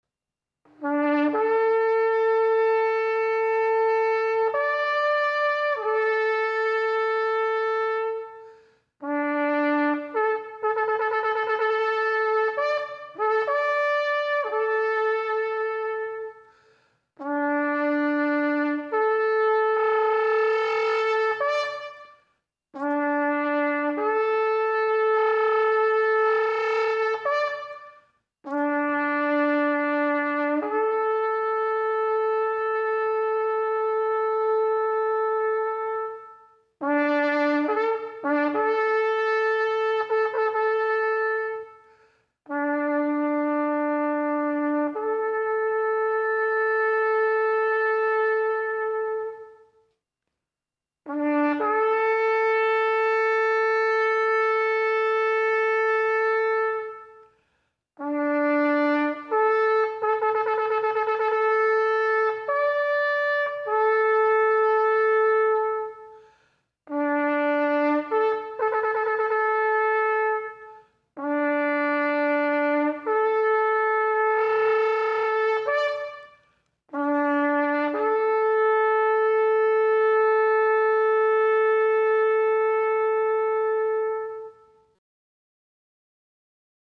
shofar.mp3